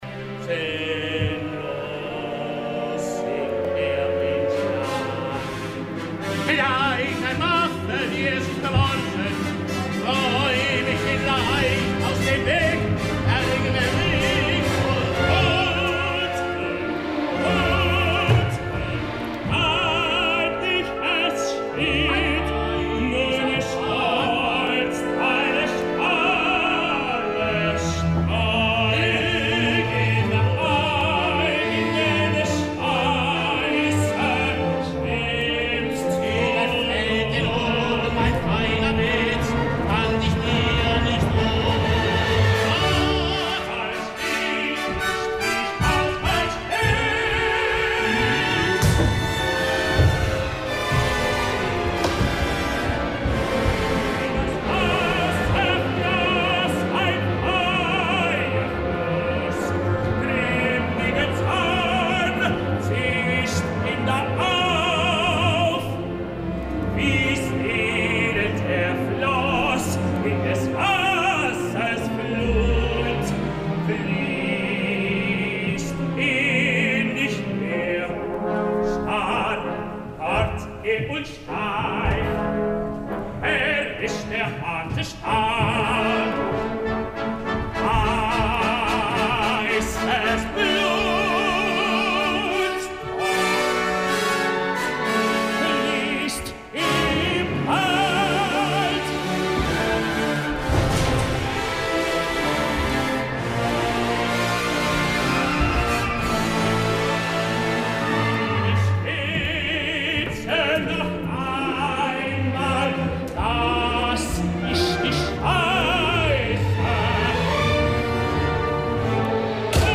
Siegfried acte 1er a Bayreuth, producció de Tankred Dorst
Després d’un any, l’única diferencia, i no és poca, ha estat contractar a Lance Ryan, un tenor canadenc que ens ha volgut vendre com la gran esperança blanca del cant wagnerià i és clar, la decepció com era previsible, ha estat notable.